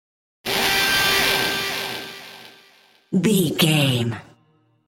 In-crescendo
Thriller
Aeolian/Minor
tension
ominous
eerie
synthesiser
Horror Synths